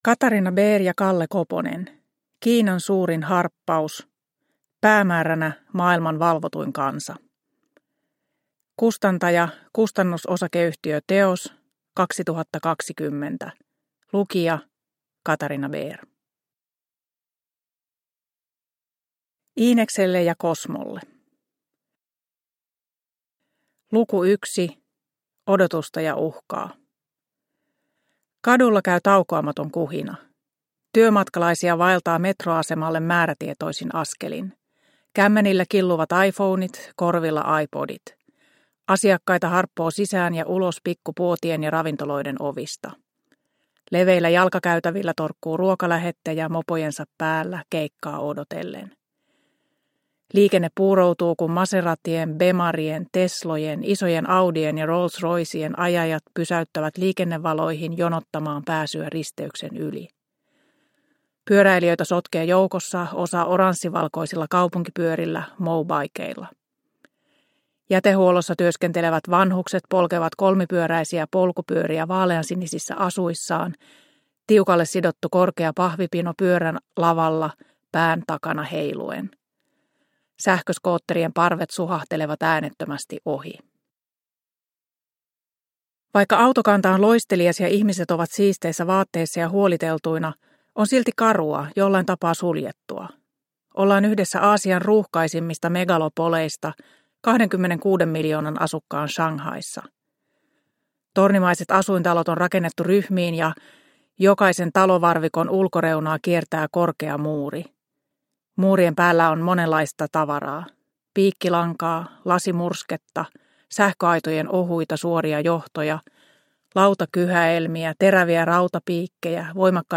Kiinan suurin harppaus – Ljudbok – Laddas ner